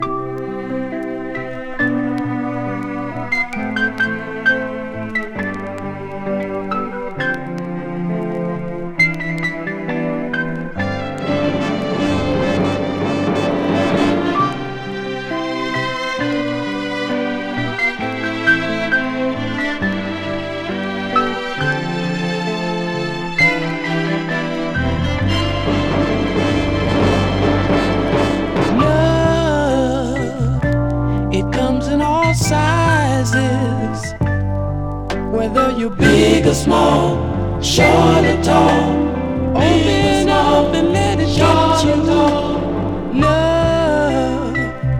Soul　USA　12inchレコード　33rpm　Stereo